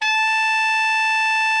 TENOR 38.wav